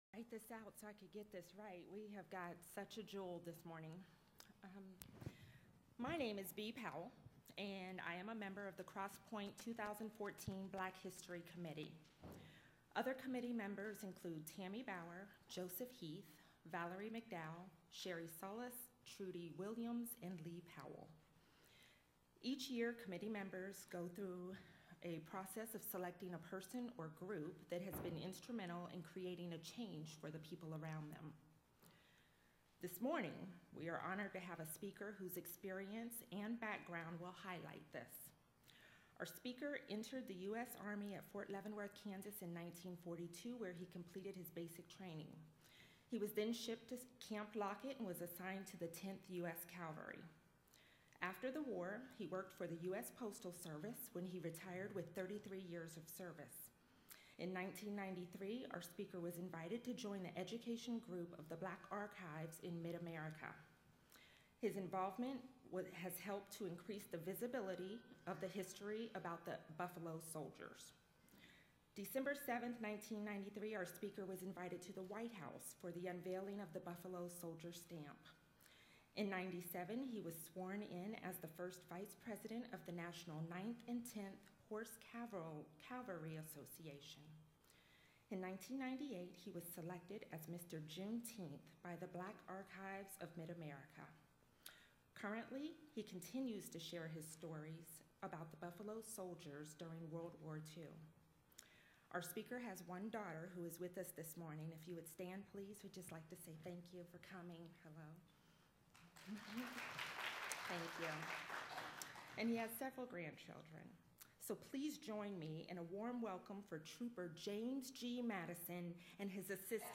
Interview
Oral History